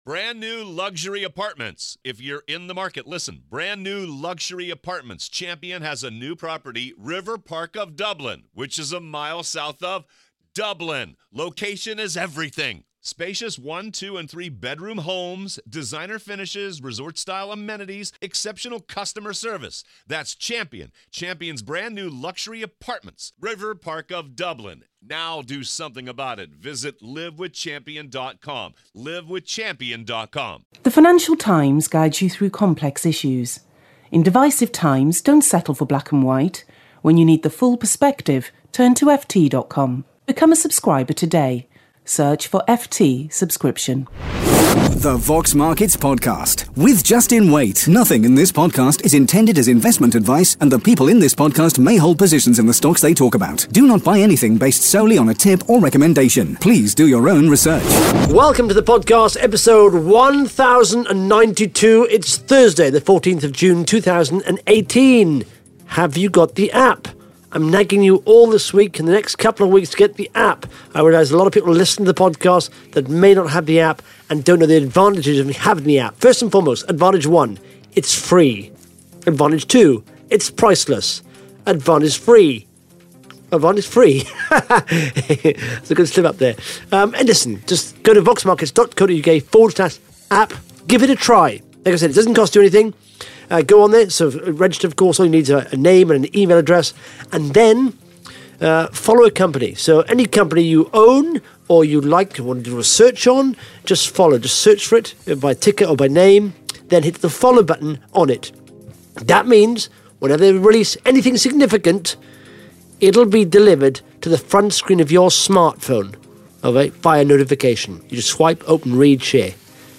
(Interview starts at 2 minute 47 seconds)